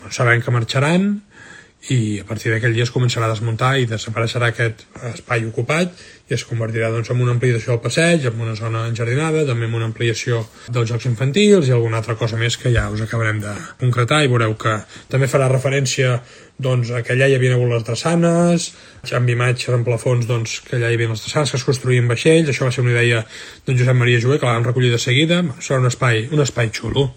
L’alcalde Marc Buch anunciava en el seu últim directe a xarxes com es farà aquesta transformació un cop les instal·lacions quedin definitivament desmuntades, aproximadament a mitjans d’aquest mes de març.